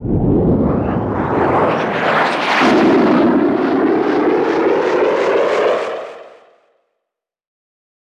jet2.wav